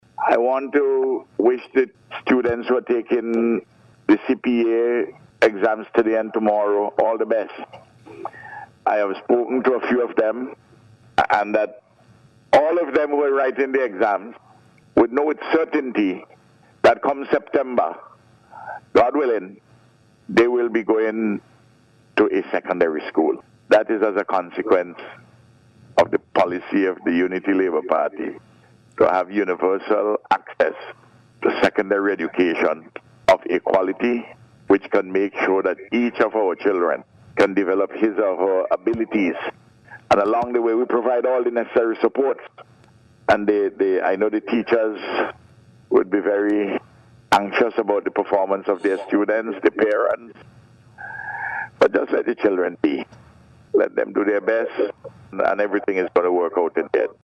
Speaking on Star Radio this morning, Prime Minister Gonsalves wished all students success in their examinations